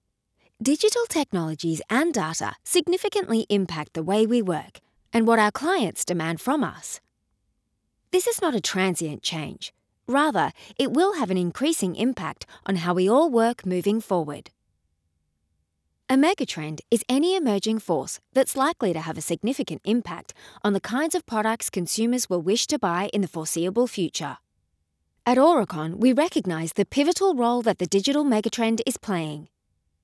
Natural, Travieso, Seguro, Amable, Cálida
Corporativo